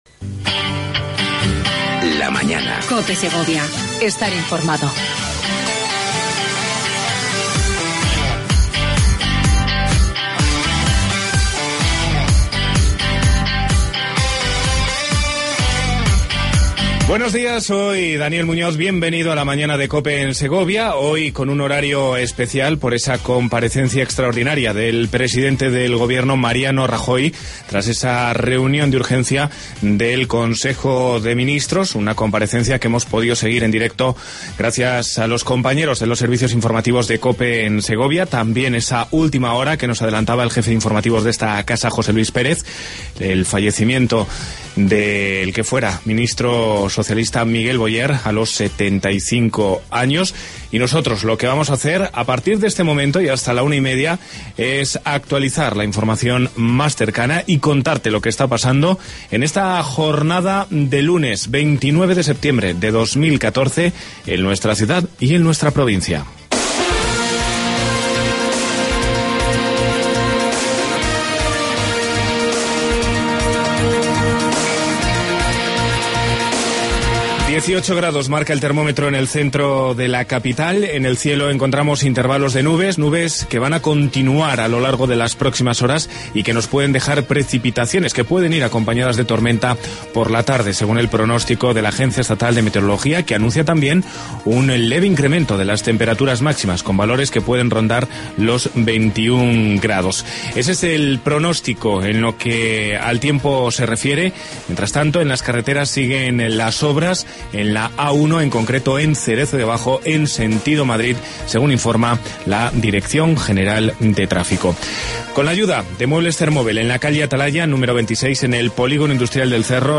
AUDIO: Avance Informativo. Felicitaciones.